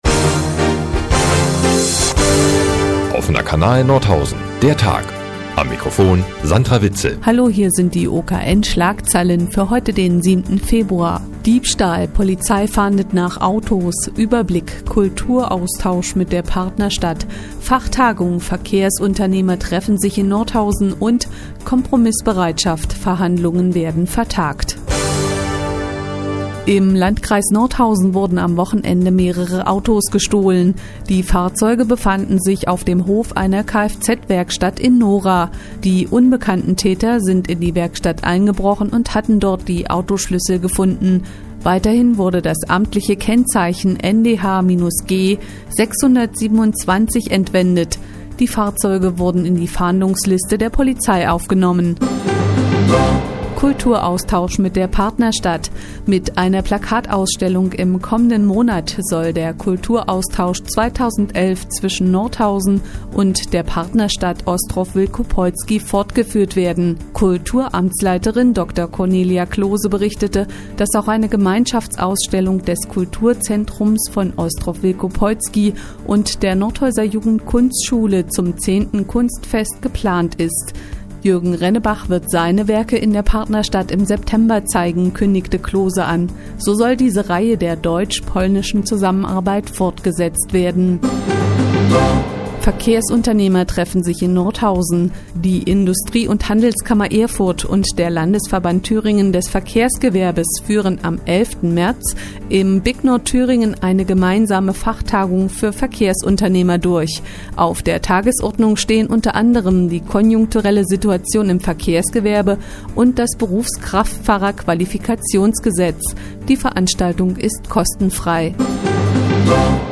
Die tägliche Nachrichtensendung des OKN nun hier zu hören.